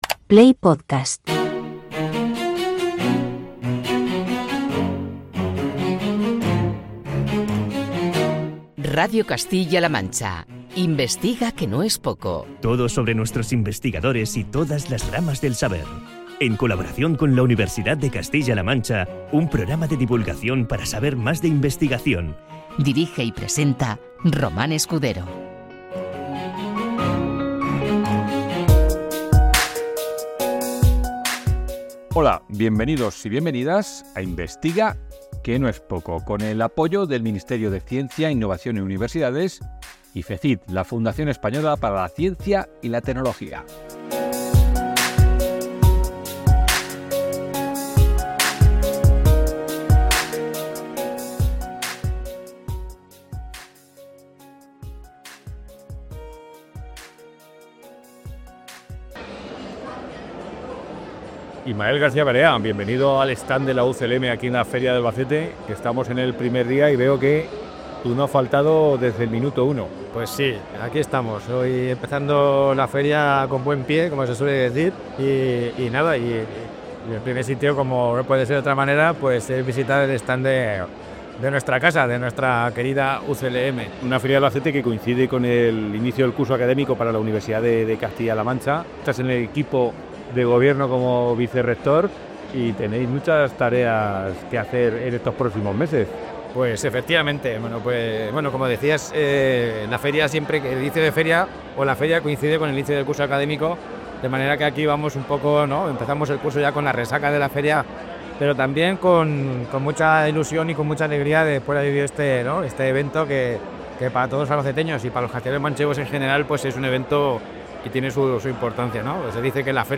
Desde el stand de la UCLM en la Feria de Albacete